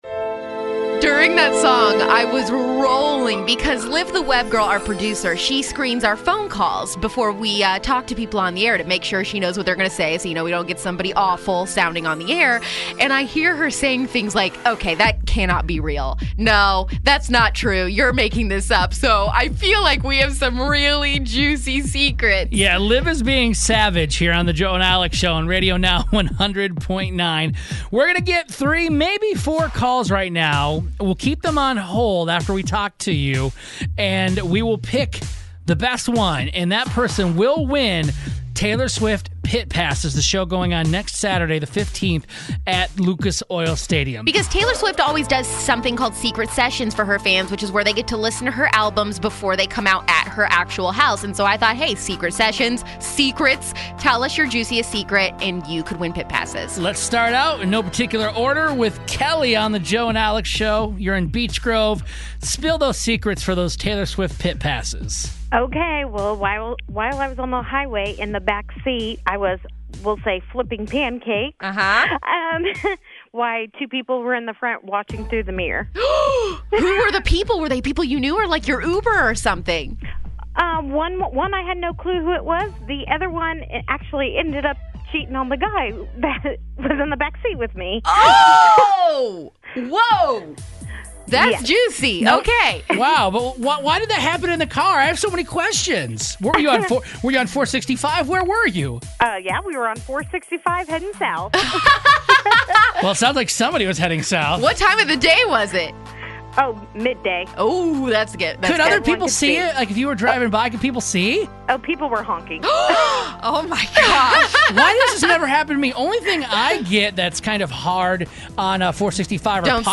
We can listeners call in and dish their JUICIEST secrets in order to win Taylor Swift pit passes.